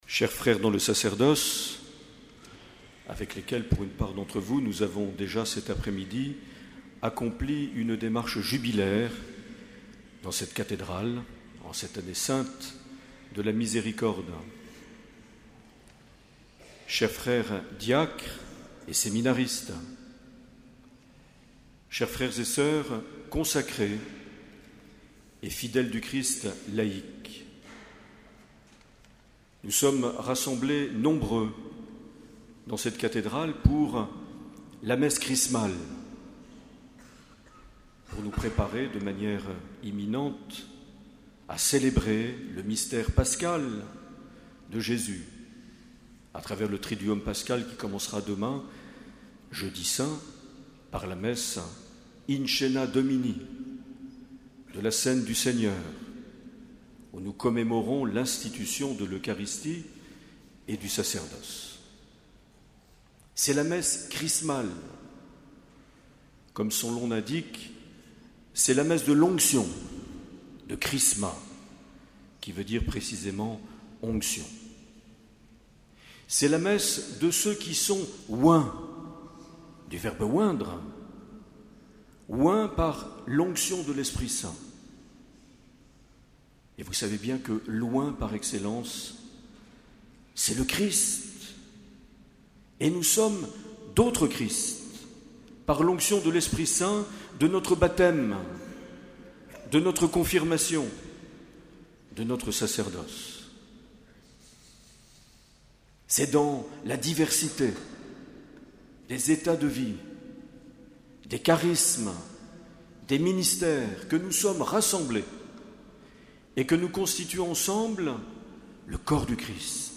23 mars 2016 - Cathédrale de Bayonne - Messe Chrismale
Les Homélies
Une émission présentée par Monseigneur Marc Aillet